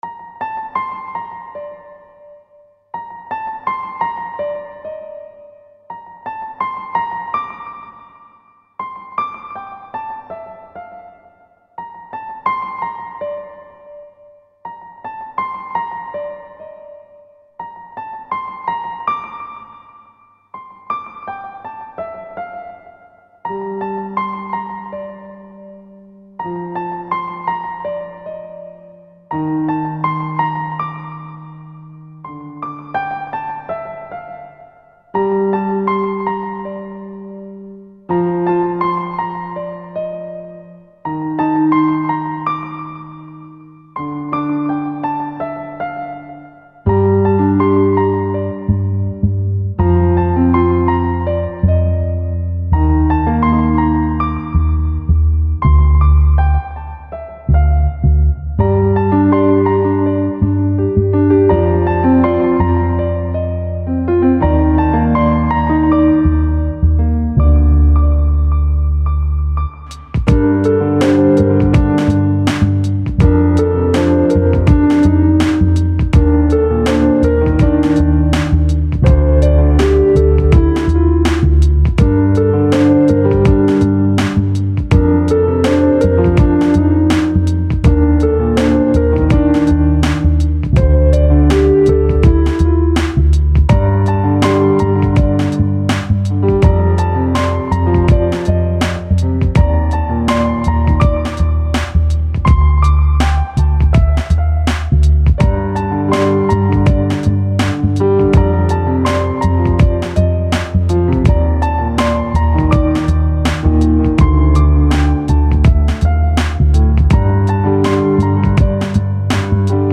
Klassicheskaya_muzyka_v_sovremennoy_obrabotke_Dozhd_i_skripka__MUSIC_LORD_.mp3